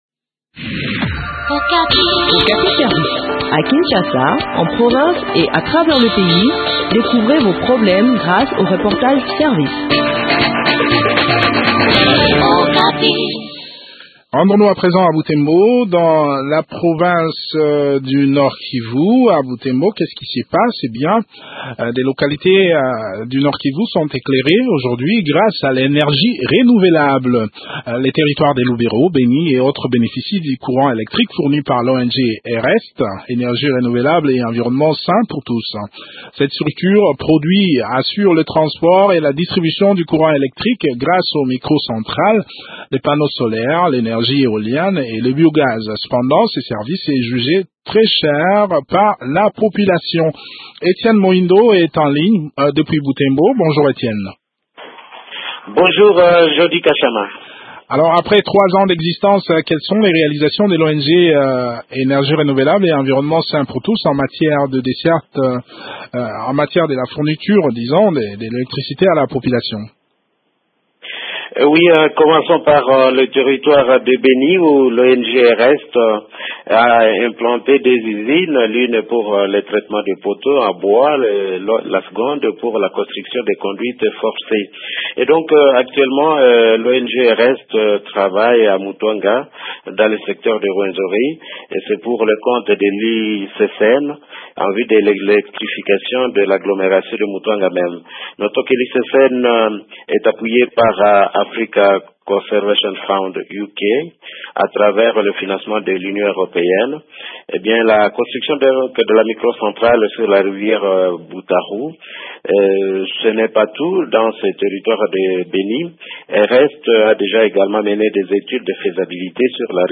Le point sur l’exécution de ce projet dans cet entretien